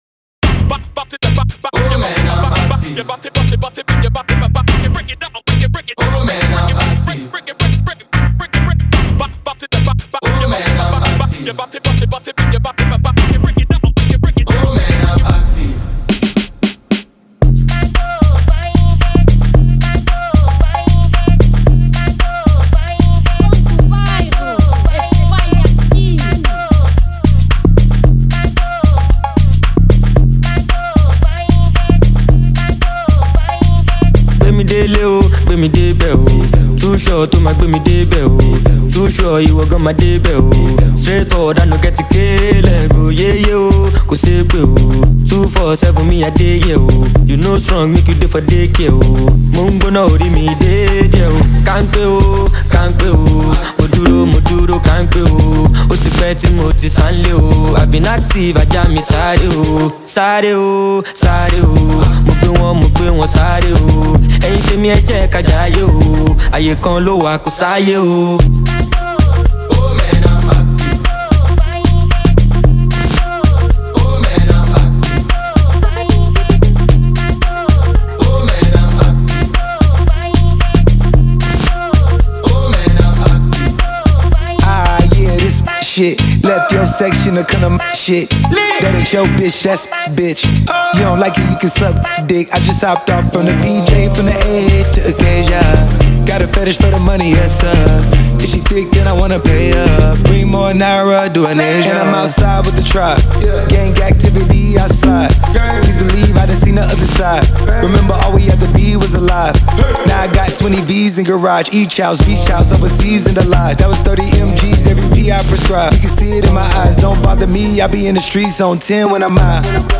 | Afro Bongo